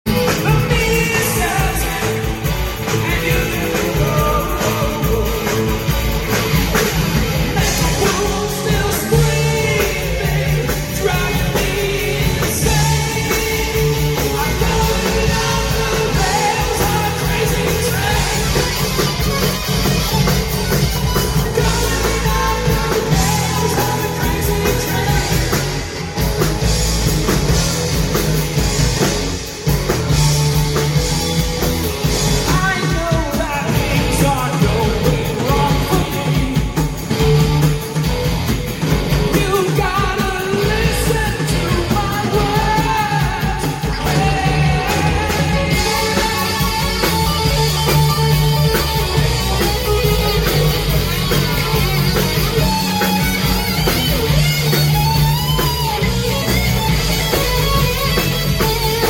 Live at the Platte River Grill MHK Sound